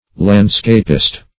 Landscapist \Land"scap`ist\, n.